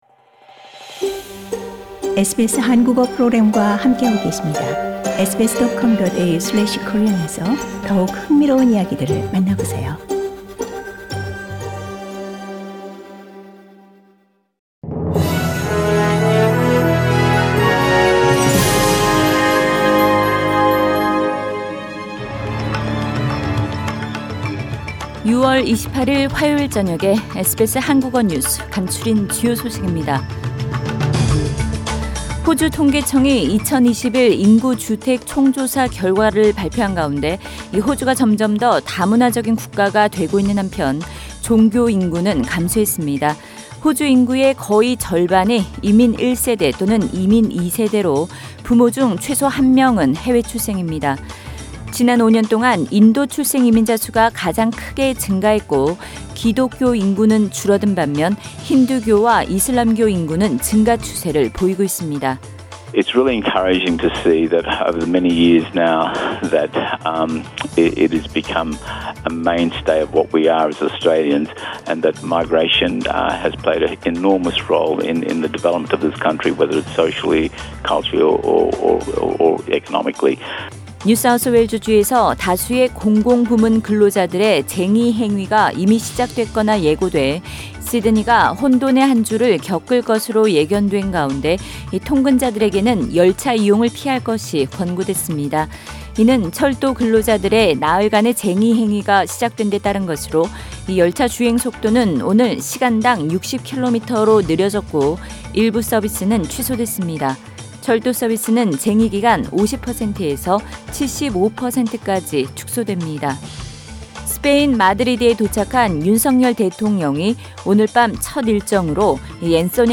2022년 6월 28일 화요일 저녁 SBS 한국어 간추린 주요 뉴스입니다.